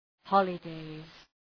{‘hɒlə,deız}